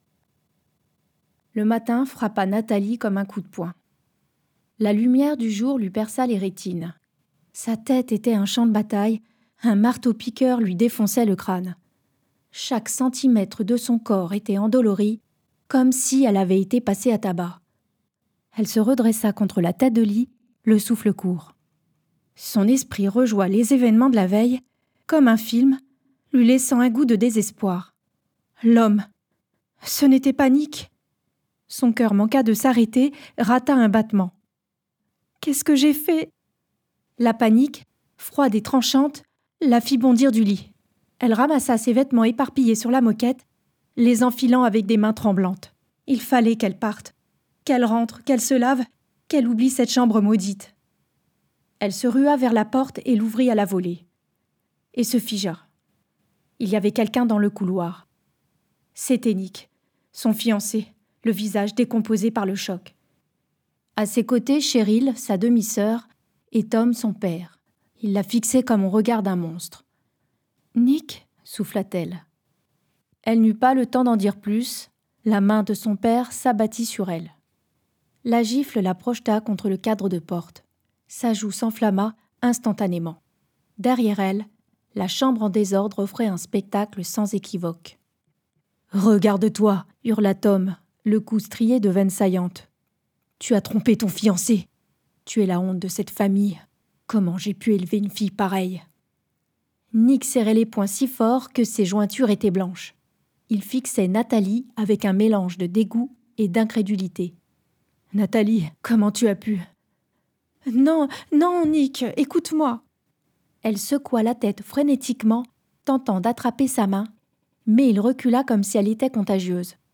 Démo livre audio thriller psychologique